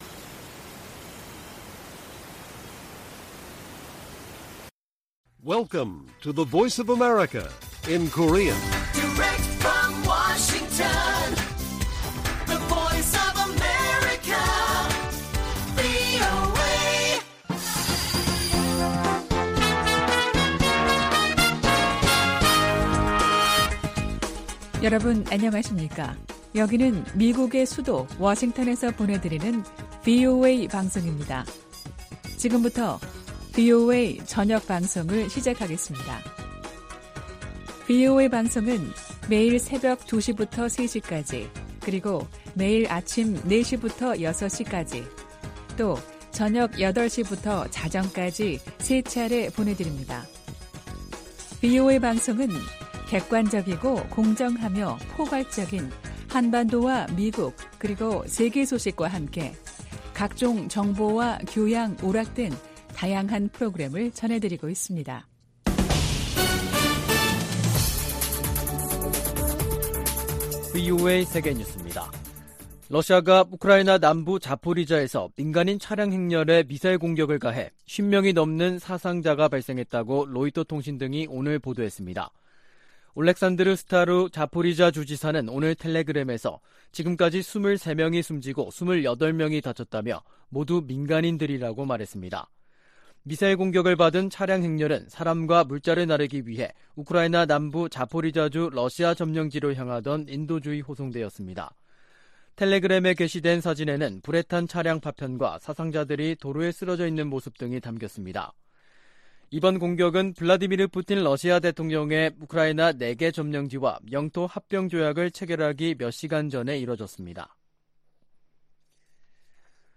VOA 한국어 간판 뉴스 프로그램 '뉴스 투데이', 2022년 9월 30일 1부 방송입니다. 백악관은 카멀라 해리스 부통령이 한국에서 미국의 확장억제 의지를 재확인했다고 밝혔습니다. 북한이 닷새 간 세 차례 탄도미사일 도발을 이어가자 한국 정부는 국제사회와 추가 제재를 검토하겠다고 밝혔습니다. 미한일이 5년만에 연합 대잠수함 훈련을 실시하고 역내 도전에 공동 대응하기로 했습니다.